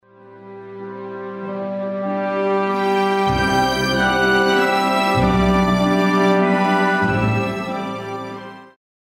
G dur　三部形式　二管の管弦楽　２分８秒
お日様が立ち昇る心象が、ホルンのＧの音とともに浮かび、